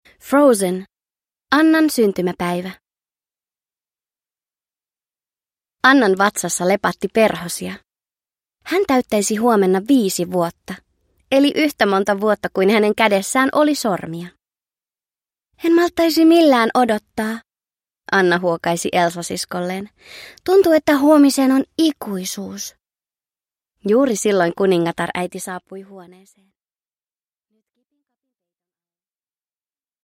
Annan syntymäpäivä – Ljudbok – Laddas ner